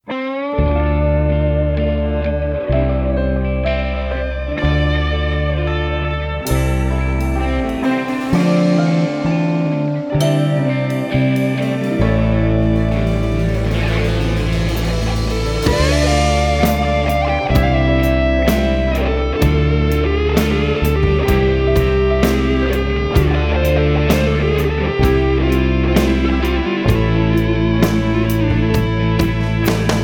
Rhythm Guitar and Fills
Bass and Keys